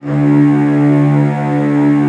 CELLOS EN2-L.wav